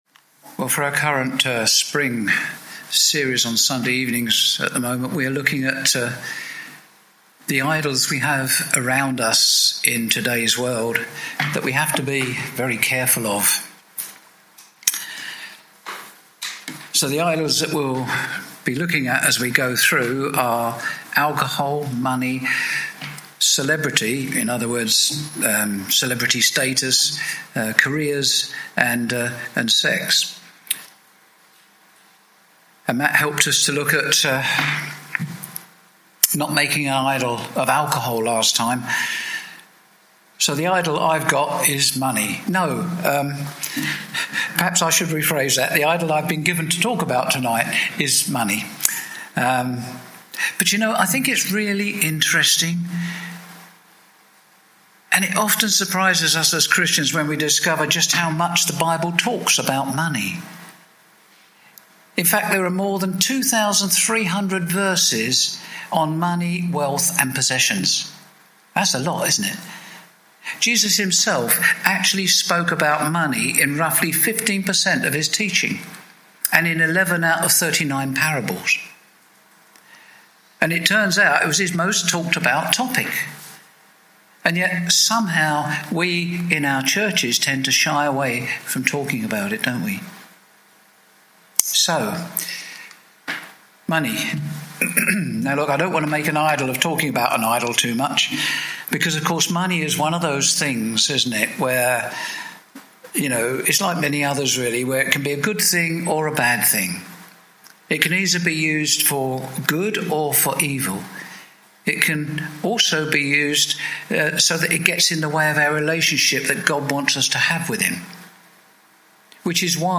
Topical Bible studies